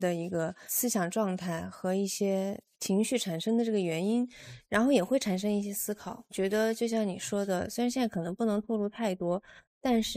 刘亦菲配音：用于影视旁白的自然AI语音
借助我们由AI驱动的刘亦菲配音，捕捉传奇女演员的优雅与情感深度，专为故事讲述和品牌格调而设计。
AI配音
影视级音色
情感深度
叙事清晰